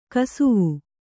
♪ kesavu